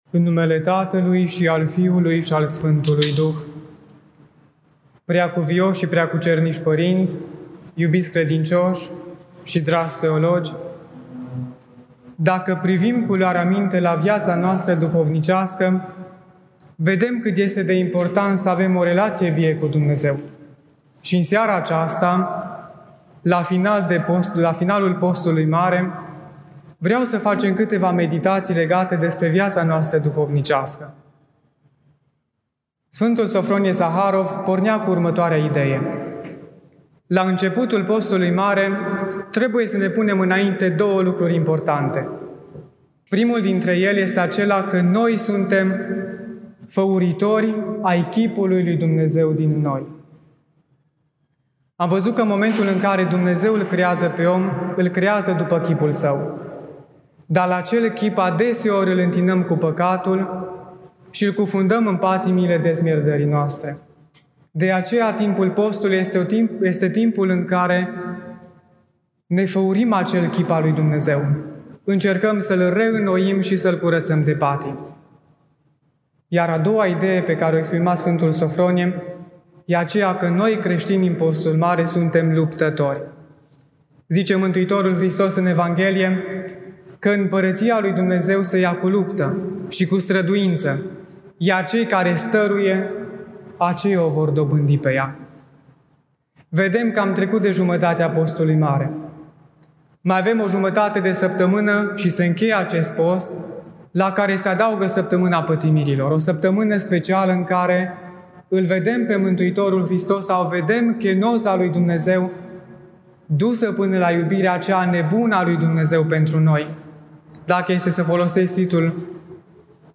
Cuvinte de învățătură „Îl mai iubim pe Hristos?”